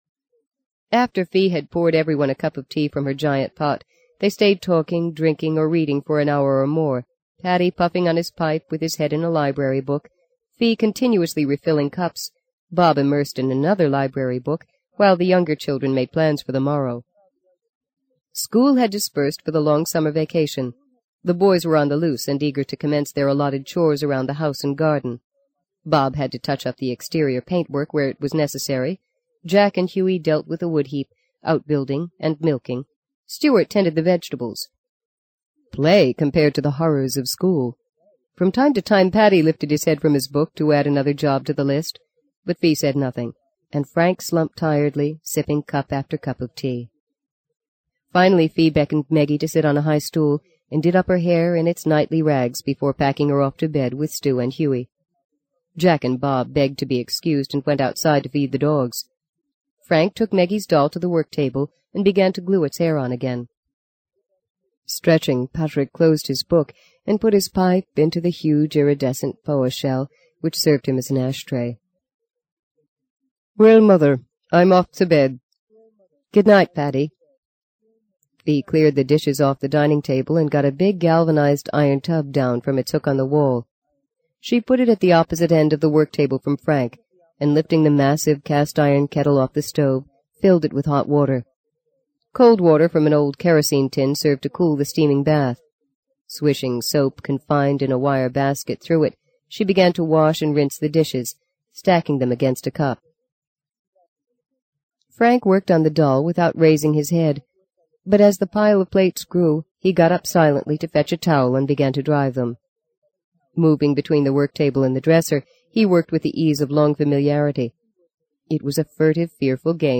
在线英语听力室【荆棘鸟】第一章 07的听力文件下载,荆棘鸟—双语有声读物—听力教程—英语听力—在线英语听力室